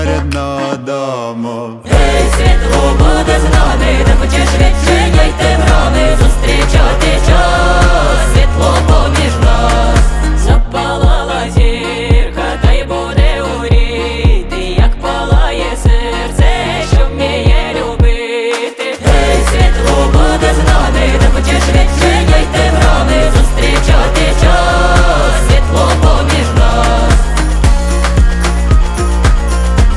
Жанр: Фолк-рок / Альтернатива / Украинские
# Alternative Folk